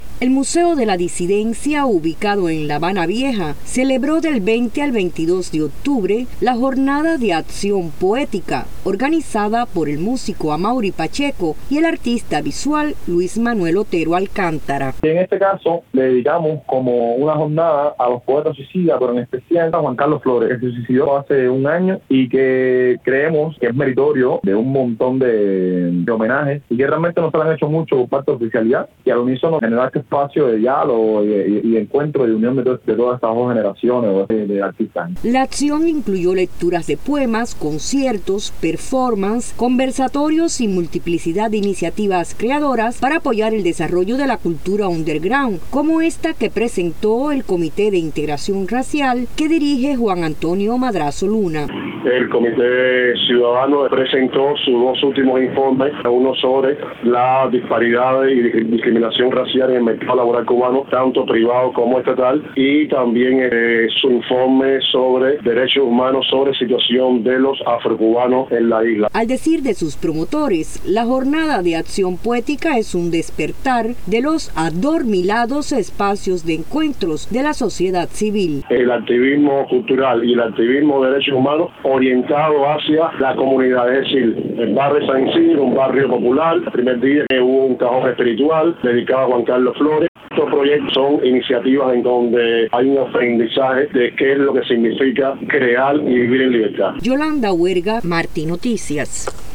Declaraciones de los promotores de la Jornada de Acción Poética